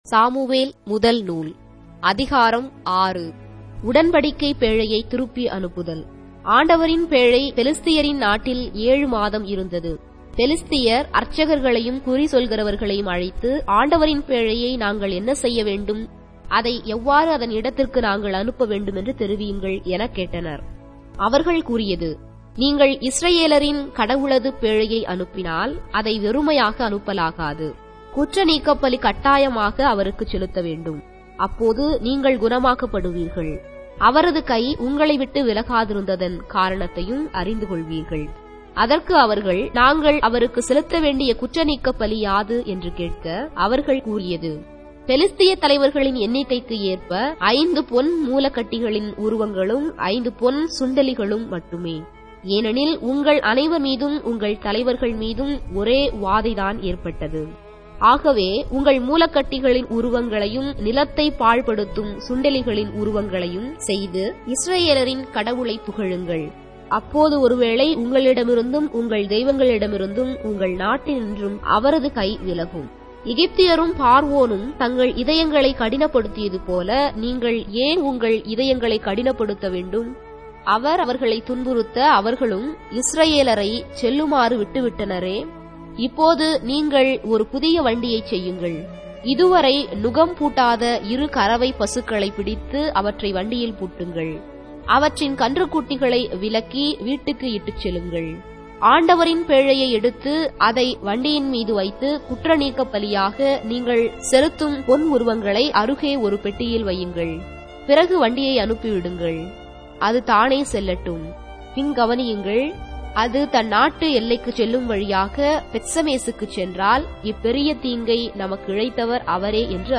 Tamil Audio Bible - 1-Samuel 6 in Ecta bible version